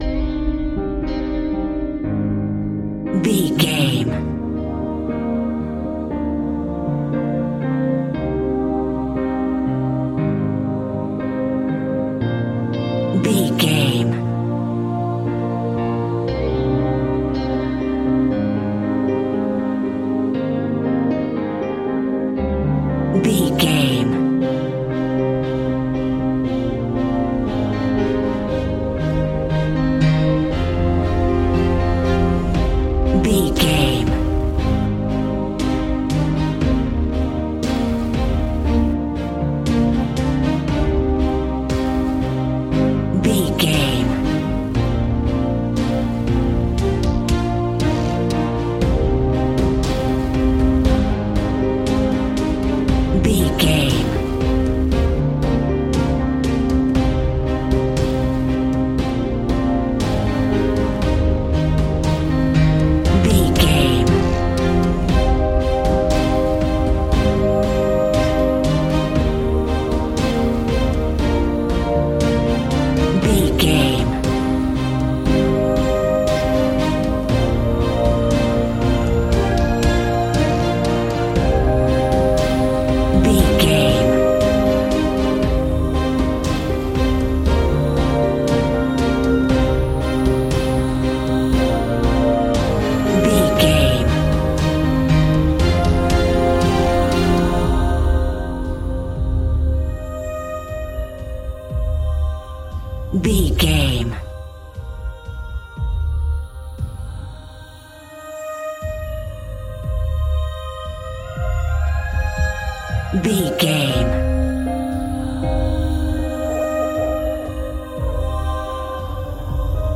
Aeolian/Minor
dramatic
epic
powerful
strings
percussion
synthesiser
brass
violin
cello
double bass